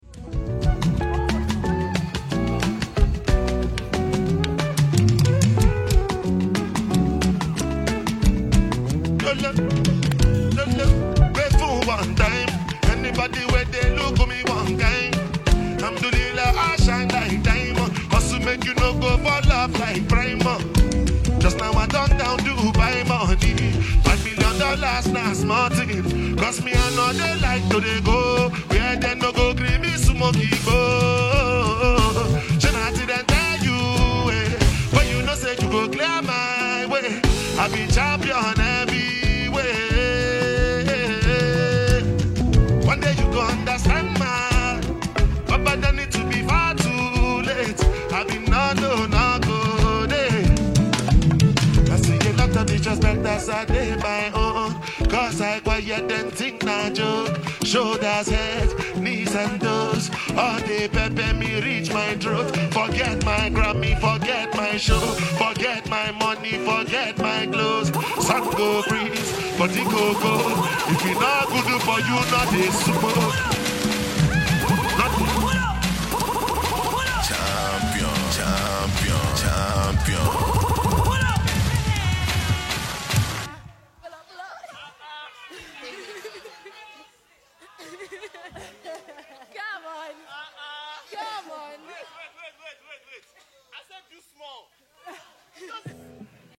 During a recent live performance